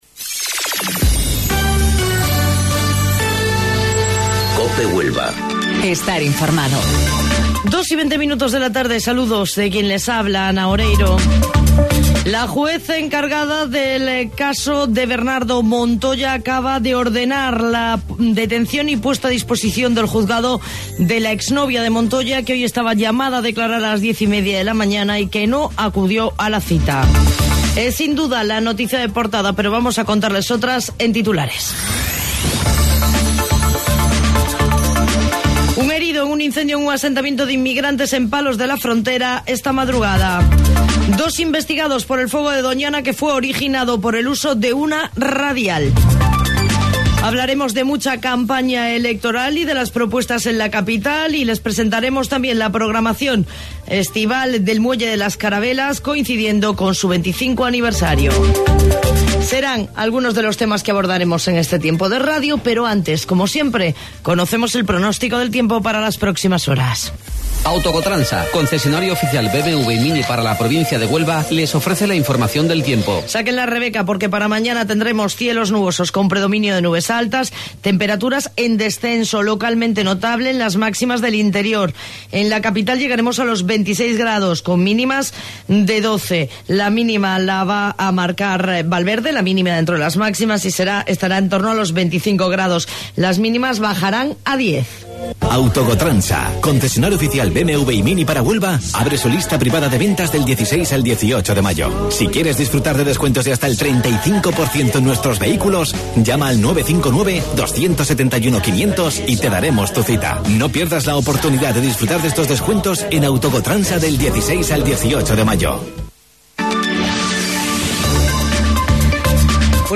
AUDIO: Informativo Local 14:20 del 16 de Mayo